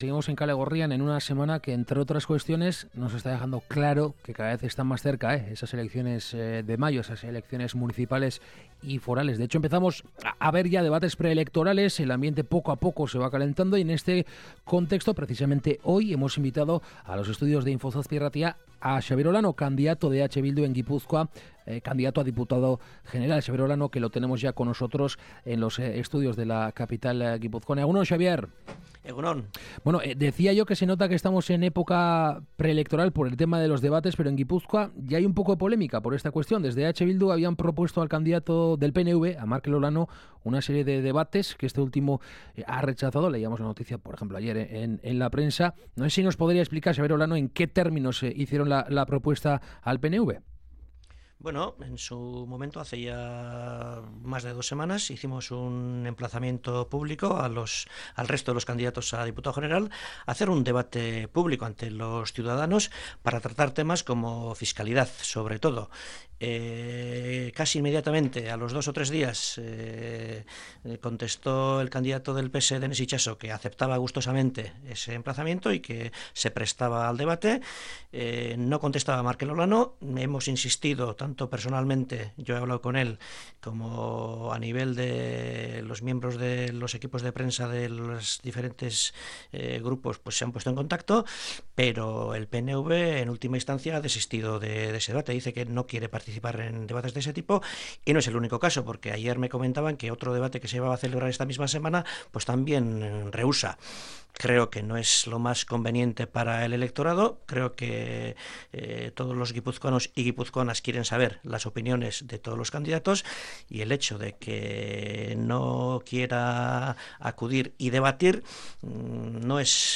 Esta mañana en Kalegorrian hemos entrevistado